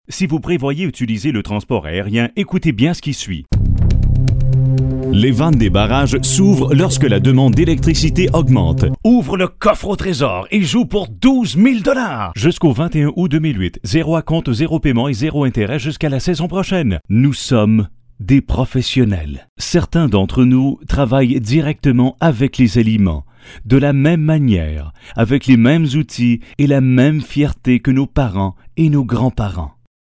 Authentic french canadian male voice
Sprechprobe: Werbung (Muttersprache):